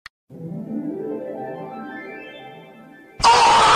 Screaming Blue Thing Sound Effect Free Download